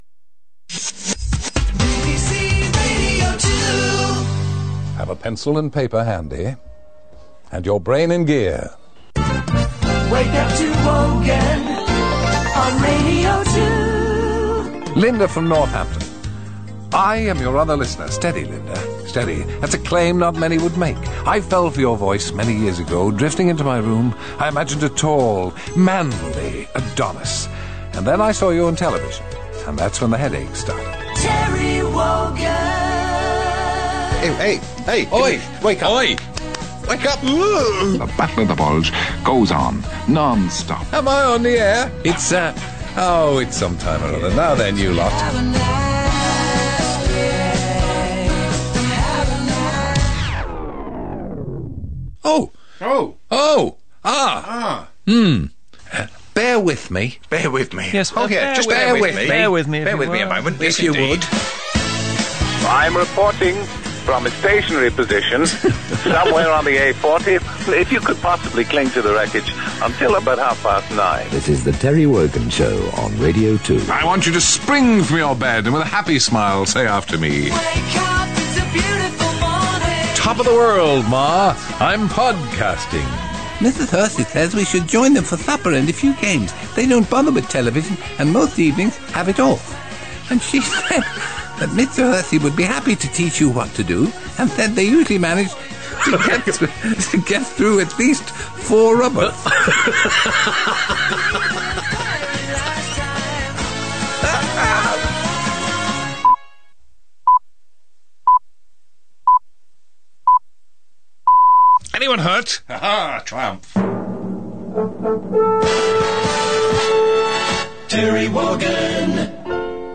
A short montage of Sir Terry's best bits as posted on the BBC Radio 2 website in December 2009.